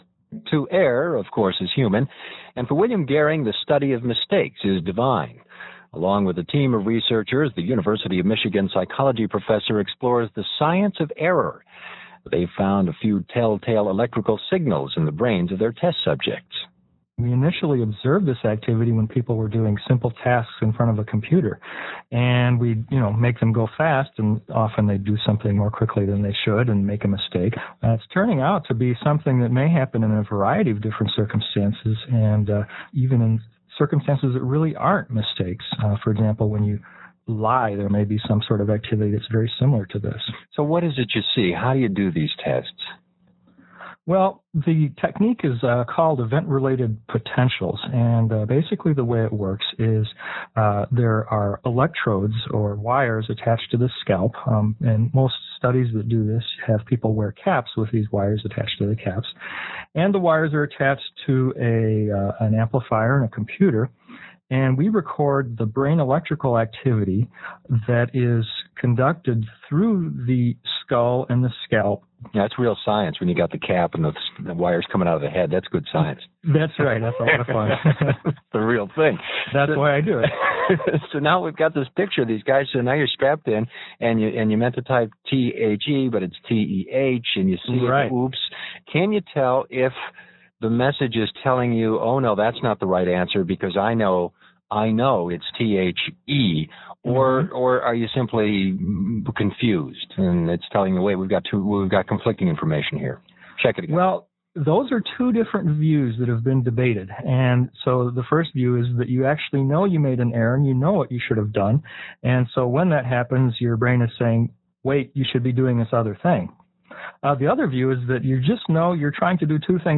NPR Interview